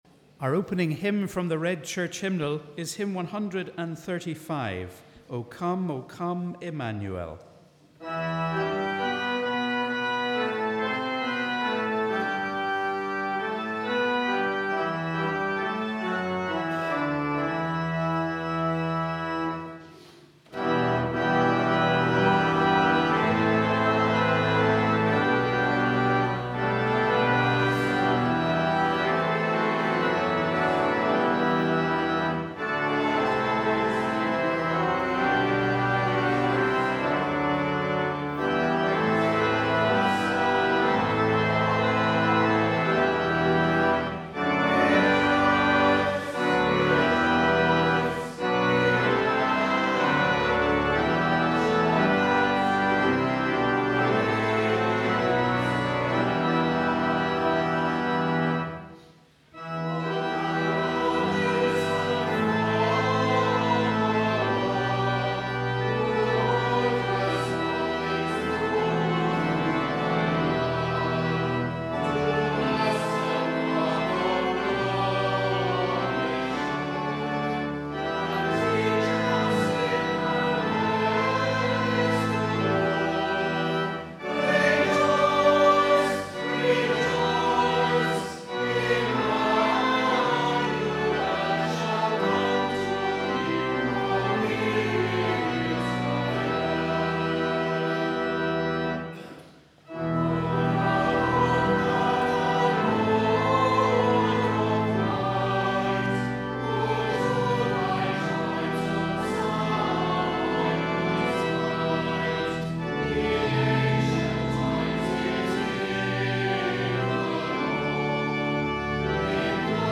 We warmly welcome you to our service of Morning Prayer for the fourth Sunday of Advent.